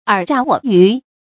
爾詐我虞 注音： ㄦˇ ㄓㄚˋ ㄨㄛˇ ㄧㄩˊ 讀音讀法： 意思解釋： 爾：你；虞、詐：欺騙。表示彼此互相欺騙。